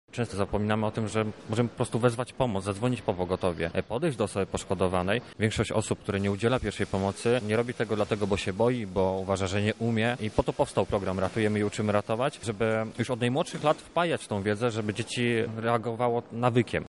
– mówi instruktor